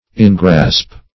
Search Result for " engrasp" : The Collaborative International Dictionary of English v.0.48: Engrasp \En*grasp"\, v. t. [imp.